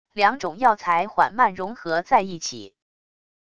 两种药材缓慢融合在一起wav音频